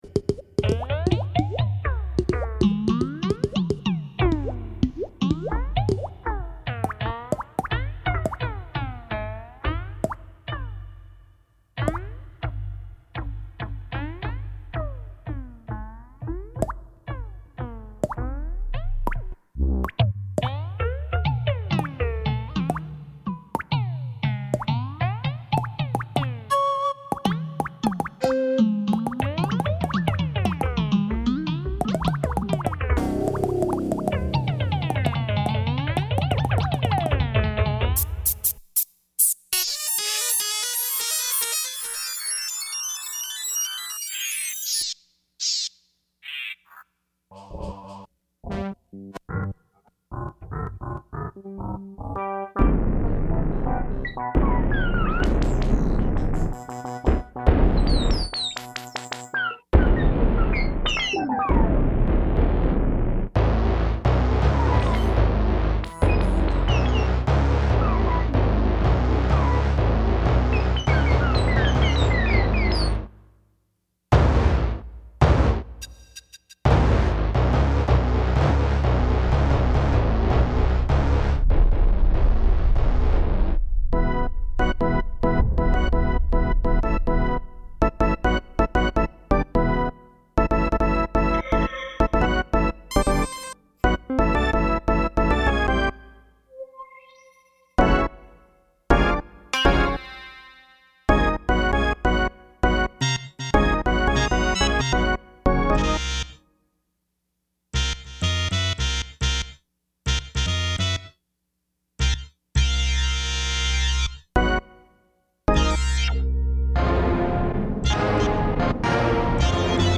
3:06 - No tempo - Some time in 1995